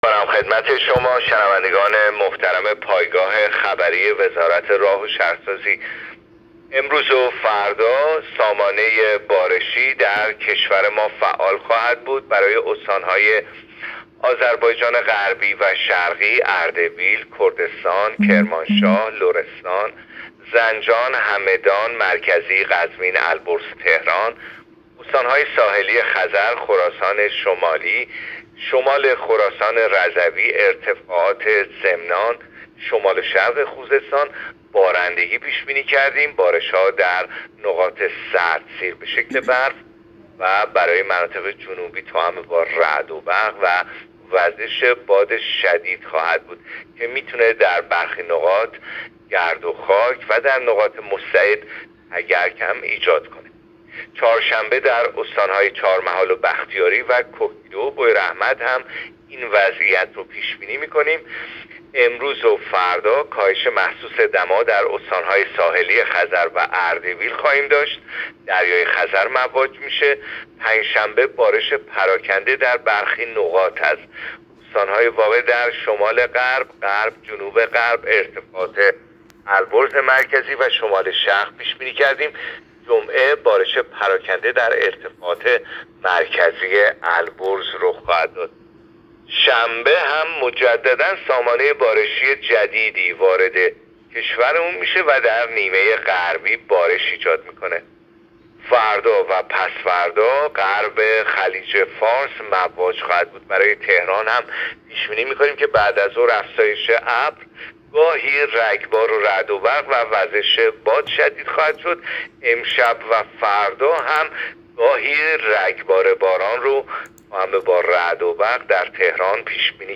گزارش آخرین وضعیت جوی کشور را از رادیو اینترنتی پایگاه خبری وزارت راه و شهرسازی بشنوید.
گزارش رادیو اینترنتی از آخرین وضعیت آب و هوای ۲۱ بهمن؛